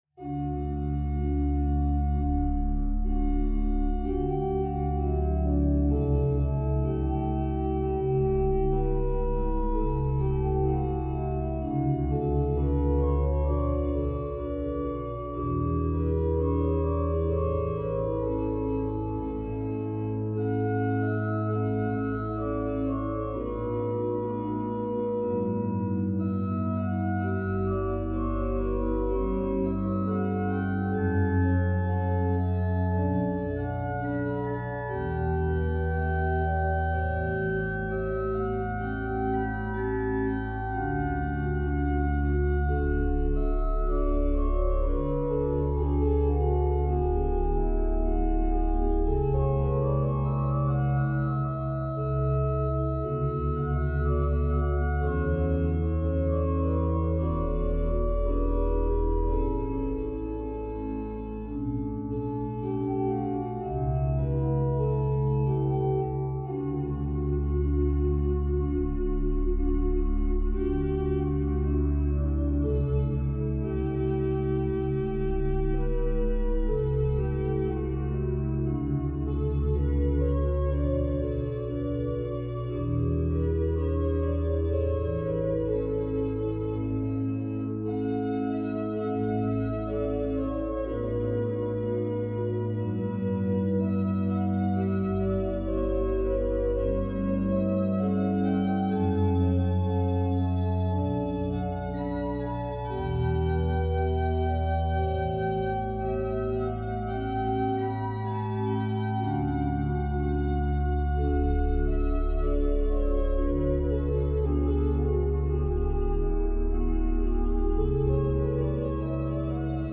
Il souhaitait disposer d’un arrangement pour orgue de cette œuvre.
L’accompagnement reflète le mouvement des croches. Avec subtilité, pour ne pas être trop « carré ».
À condition de choisir un jeu chantant.